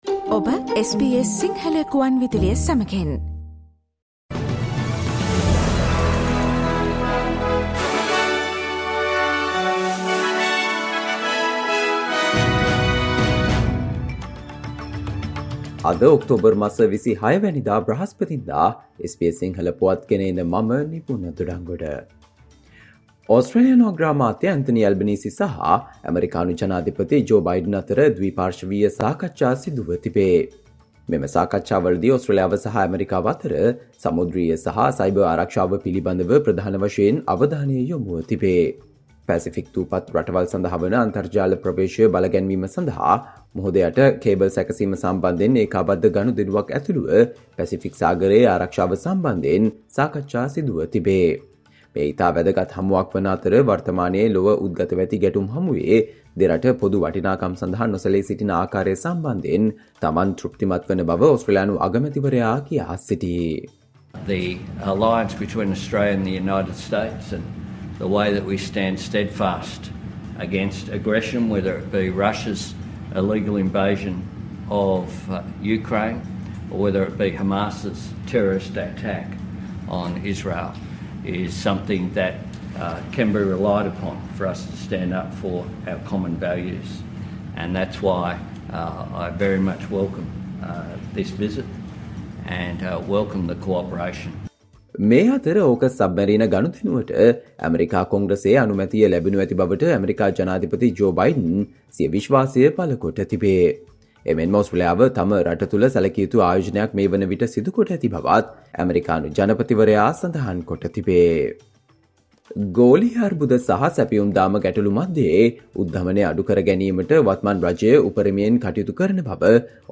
Australia news in Sinhala, foreign and sports news in brief - listen Sinhala Radio News Flash on Thursday 26 October 2023.